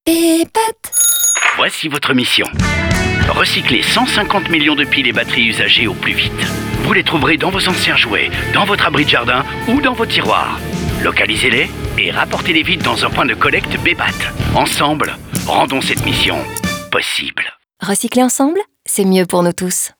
Cette campagne sera visible durant un mois sur différents supports : outdoor, print, social media, bannering et aussi via des spots radio et différentes plateformes de streaming.
Bebat - Mission Possible - radio FR.wav